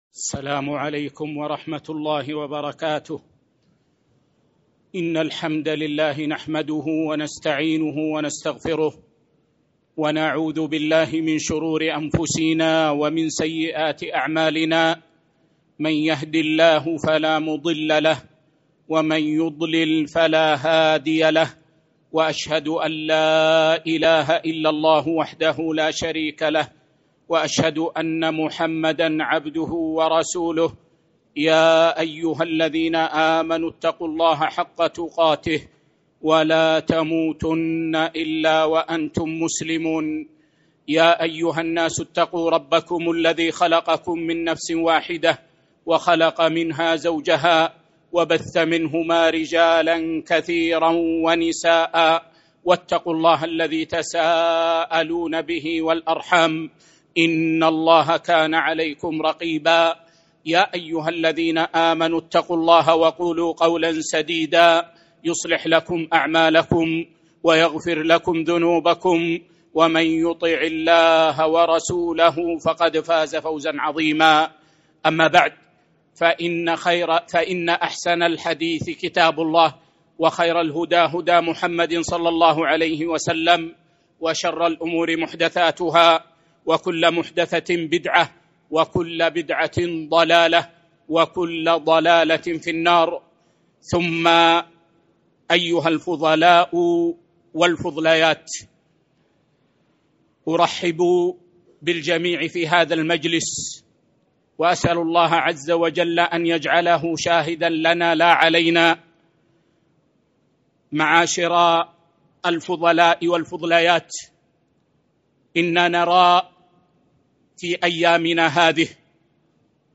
محاضرة: لماذا نحذر من جماعة الإخوان المسلمين؟ | بمسجد قباء ١٤٤٦/6/1 هـ